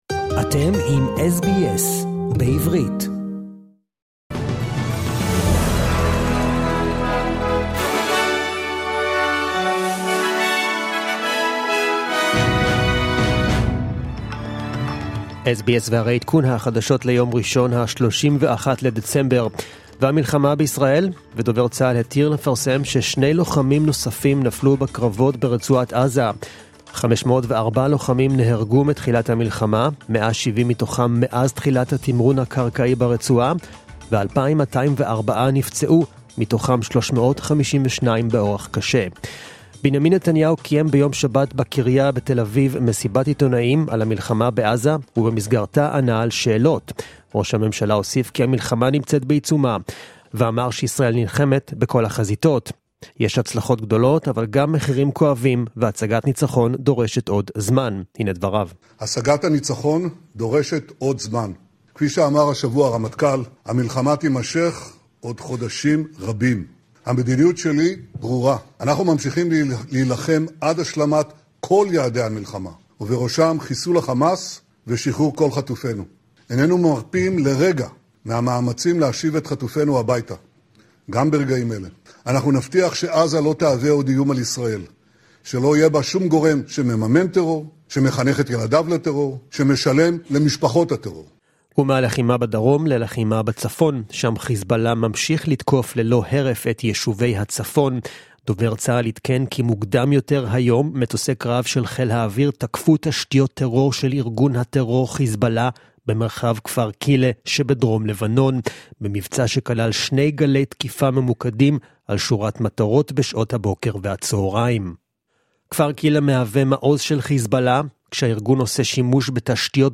The latest news in Hebrew, as heard on the SBS Hebrew program